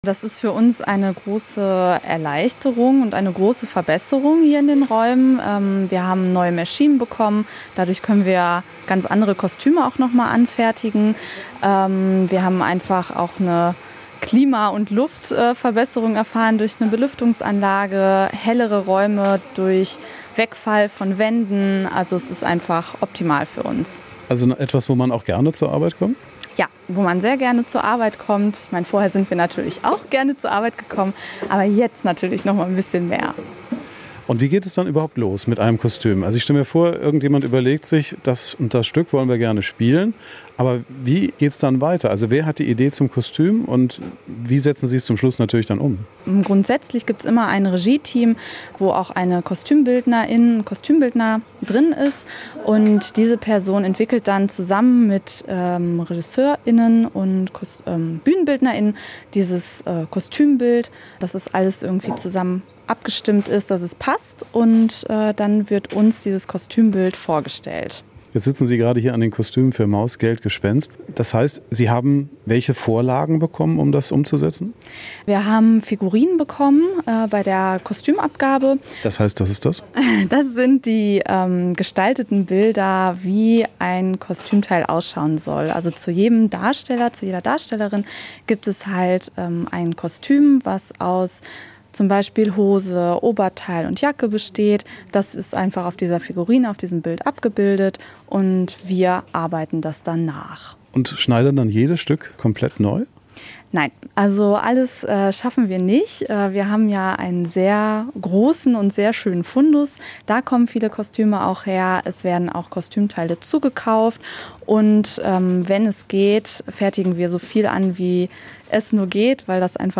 Surrende Nähmaschinen, dampfende Bügeleisen und ganz viel Kreativität. Nach anderthalb Jahren erstrahlt die Kostümwerkstatt des Grillo Theaters im neuen Glanz.